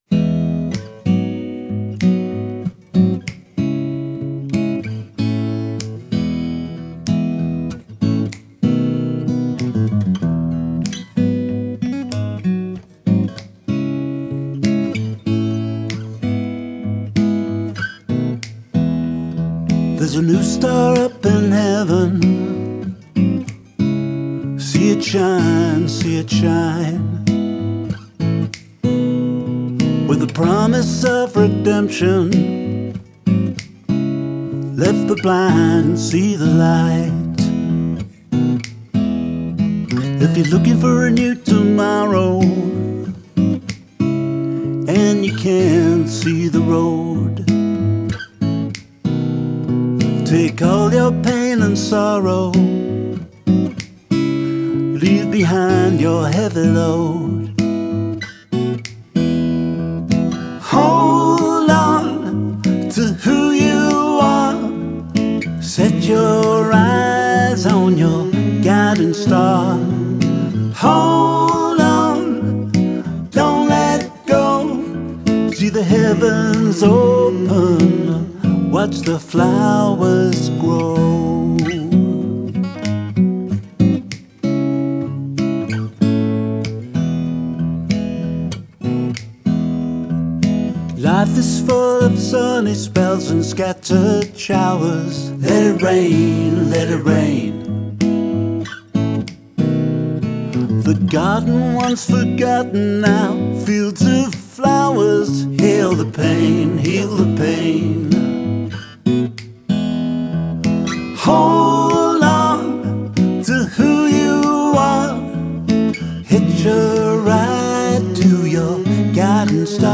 is just a delightful, positive little track